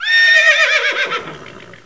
SOUND\HORSE2.WAV